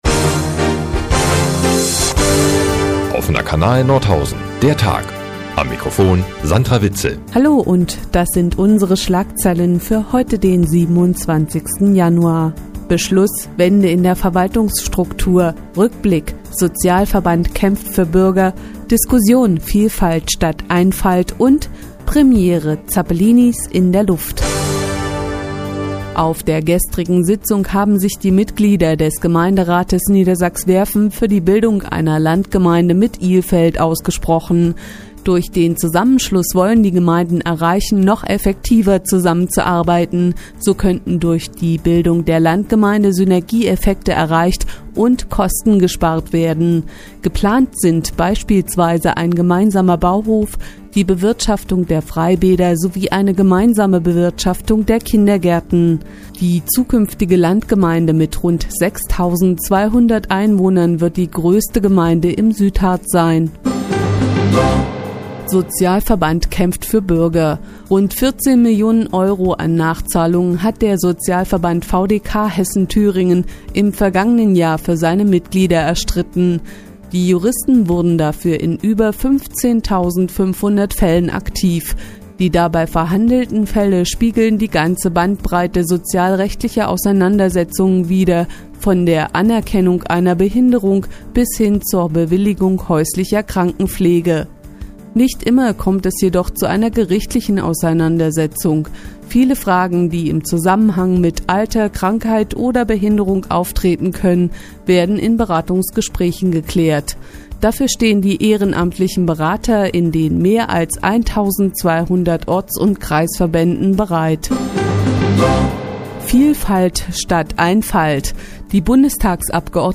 Die tägliche Nachrichtensendung des OKN ist hier in der nnz zu hören.